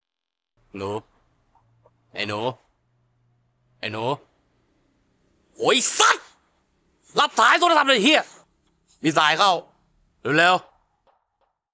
เสียงรอสายไลน์ กวนๆ
ริงโทนที่กวนส้นตีนที่สุด เสียงรอสายไลน์ ฟรี
หมวดหมู่: เสียงเรียกเข้า